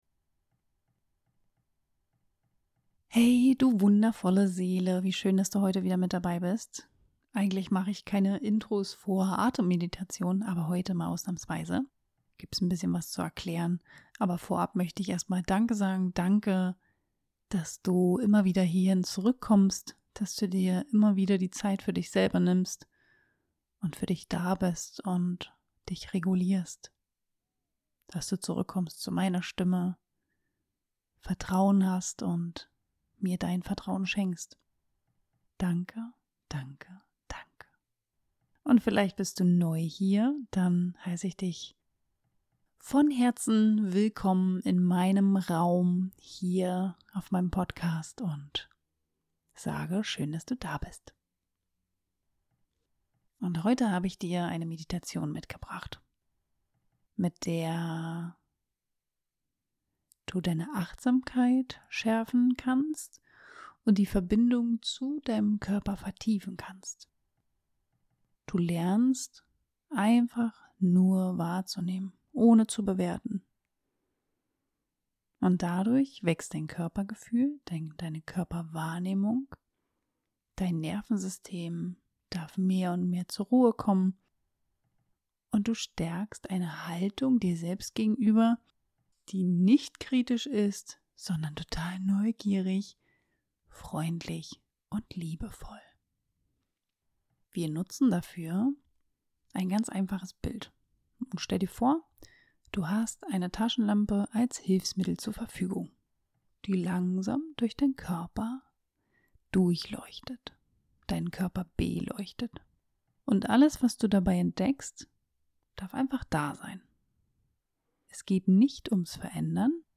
In dieser Atemmeditation begleite ich dich Schritt für Schritt zurück in deine Mitte. Du lernst, deinen Körper bewusst wahrzunehmen, ohne ständig ins Bewerten zu rutschen.